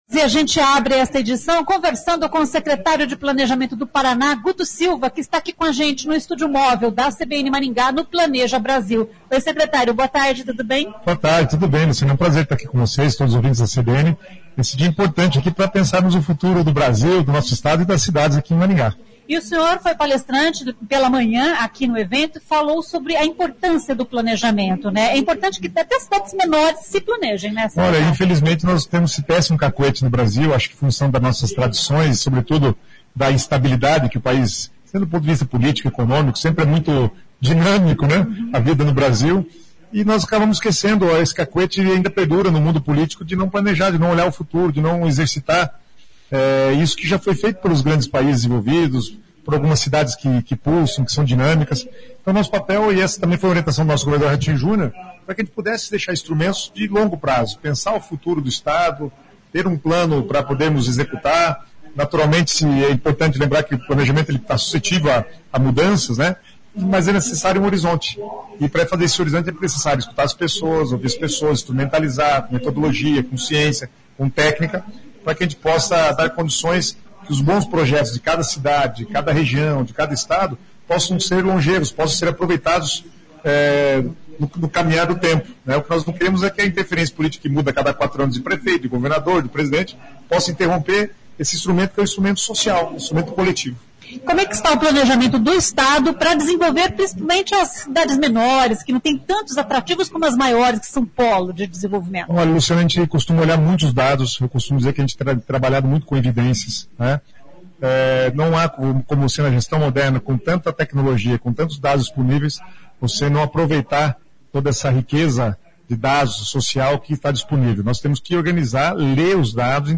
O secretário de Planejamento do Paraná, Guto Silva, diz que os municípios precisam se planejar para crescer e reter os habitantes.